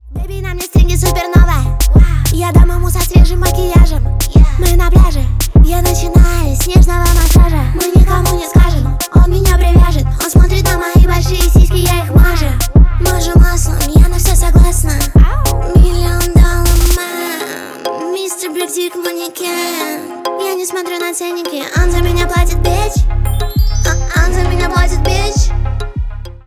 женский рэп